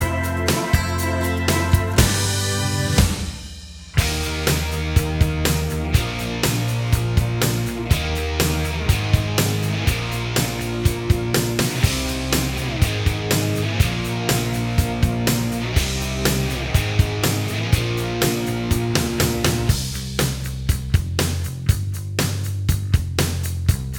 Minus All Guitars Pop (1980s) 3:23 Buy £1.50